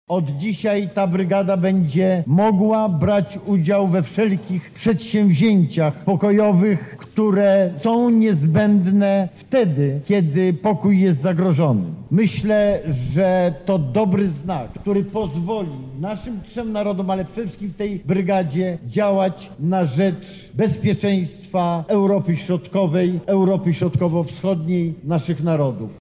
Macierewicz – mówi Antoni Macierewicz, Minister Obrony Narodowej.